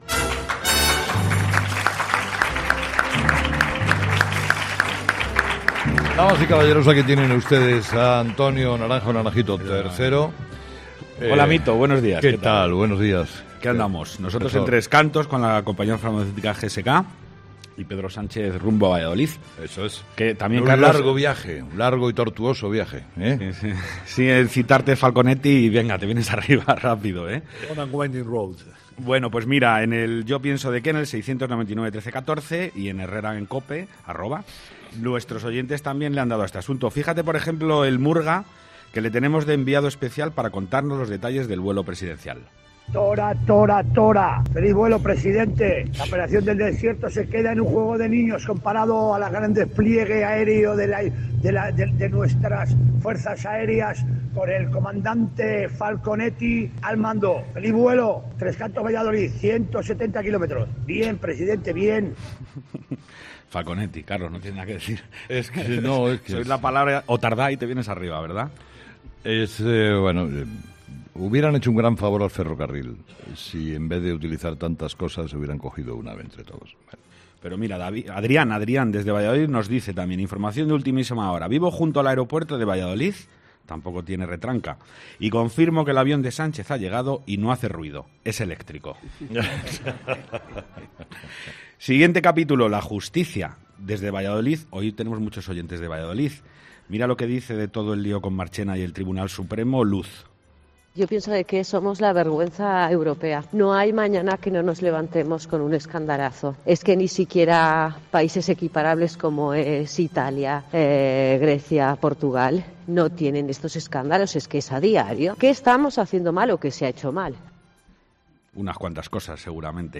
Un día más, los seguidores del programa han alzado la voz para analizar de manera inteligente los temas de actualidad. El gran protagonista ha sido el presidente, ya bautizado por muchos como "el comandante falconetti" , quien no duda en usar el Falcon para un desplazamiento de apenas 180 kilómetros, Madrid-Valladolid.